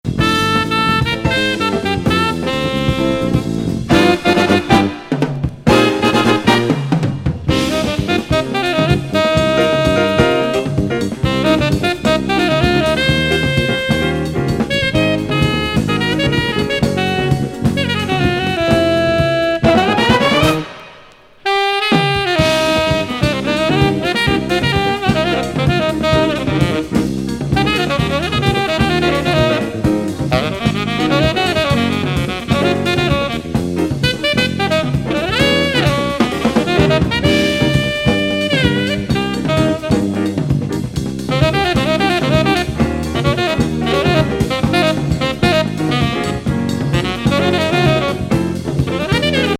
ブラジリアン・アレンジが◎